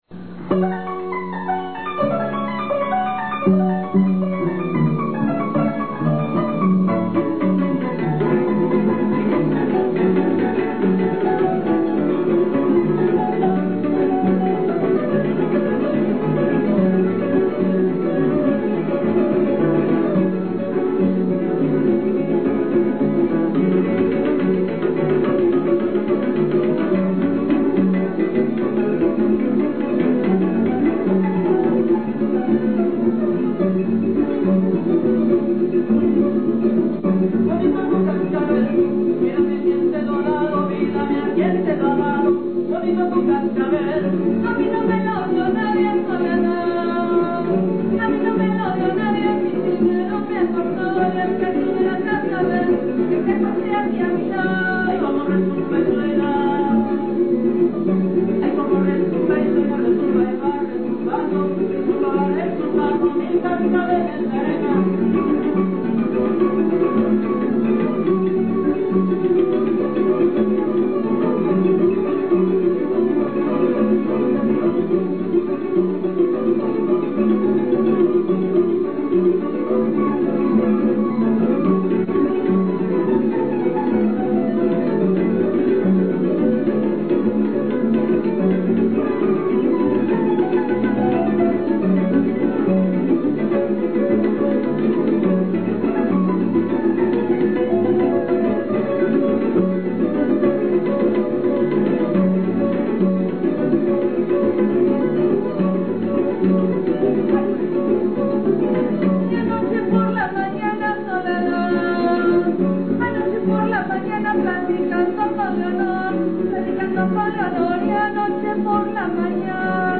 Grupo Chaneque
requinto
arpa, al "más puro estilo marisquero"